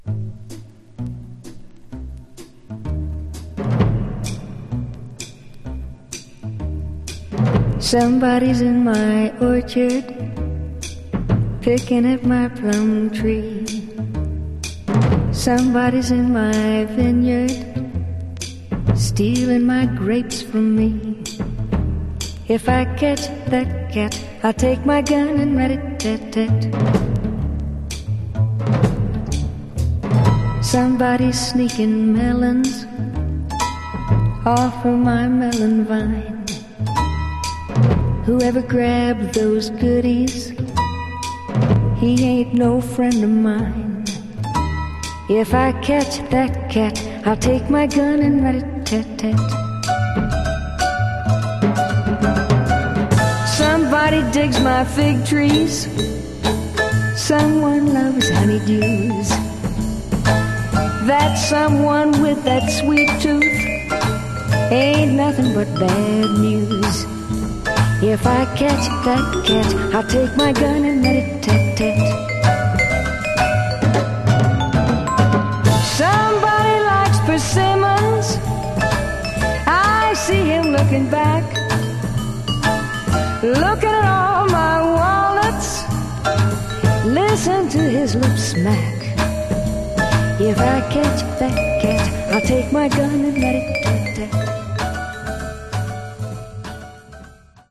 Category: RnB